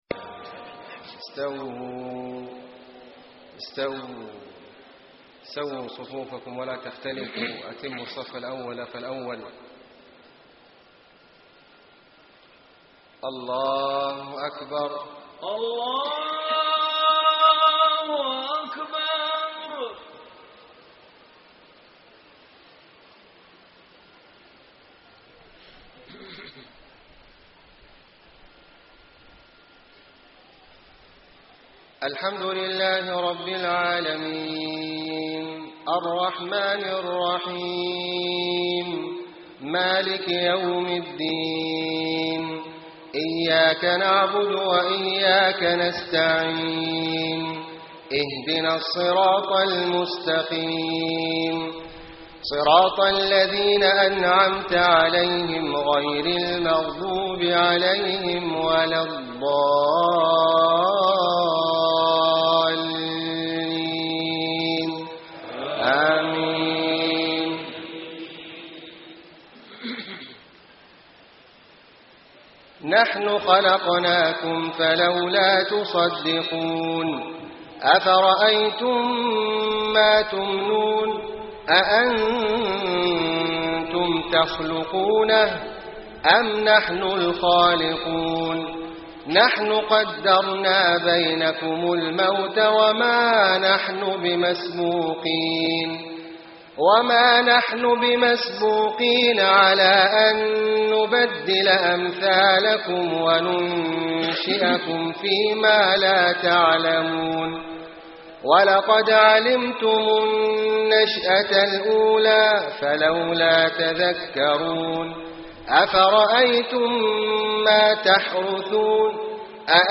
صلاة العشاء 1-6-1434 من سورة الواقعة > 1434 🕋 > الفروض - تلاوات الحرمين